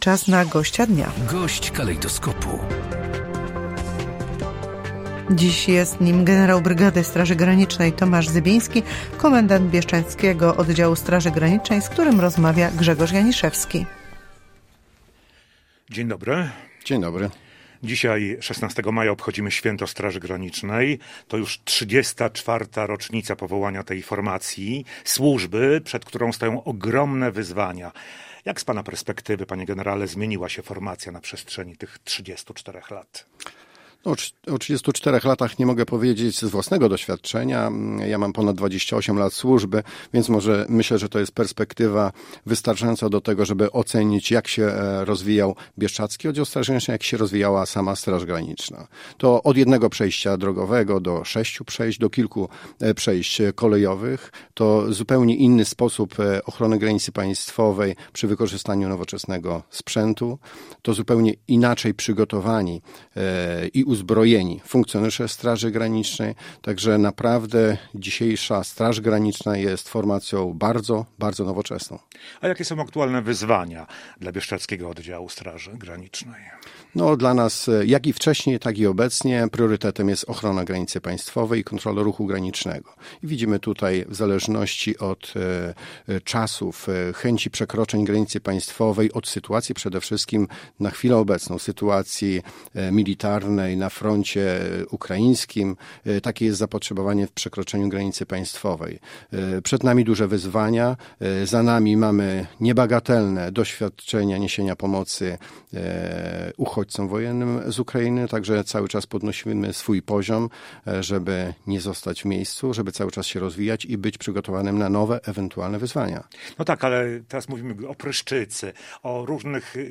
Z komendantem Bieszczadzkiego Oddziału Straży Granicznej generałem brygady SG Tomaszem Zybińskim rozmawiał